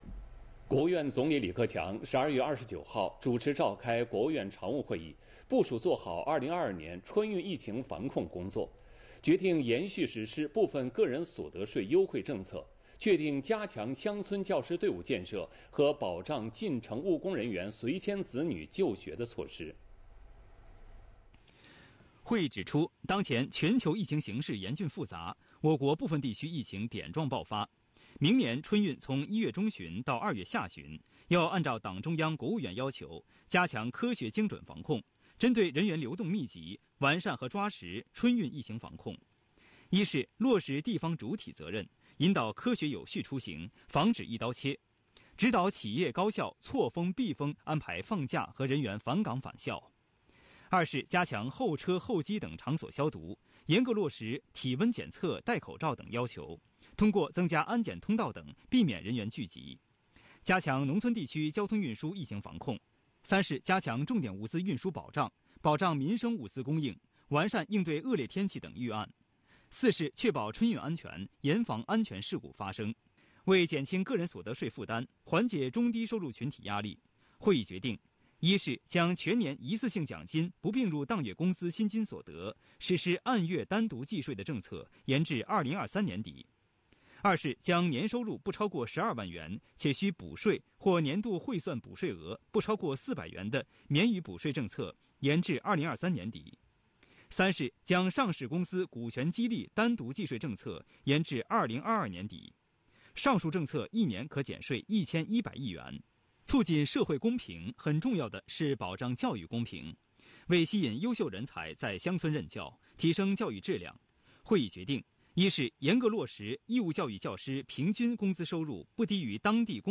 视频来源：央视《新闻联播》